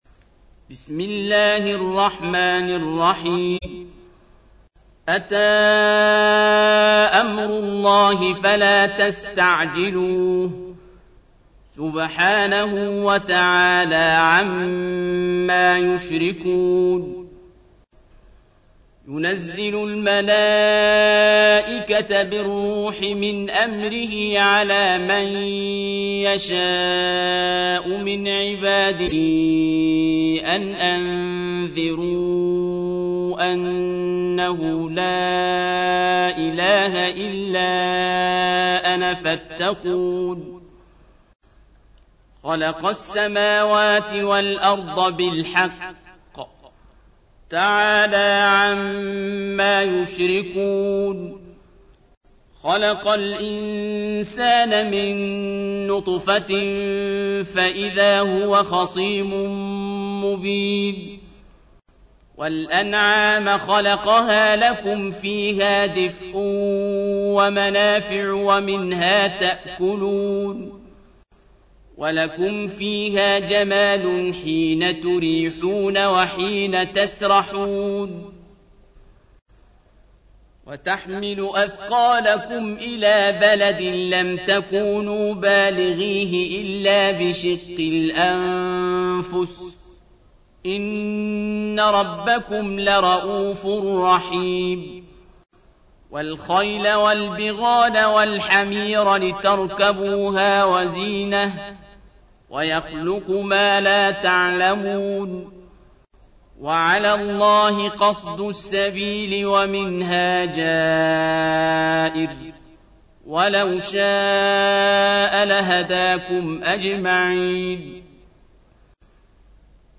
تحميل : 16. سورة النحل / القارئ عبد الباسط عبد الصمد / القرآن الكريم / موقع يا حسين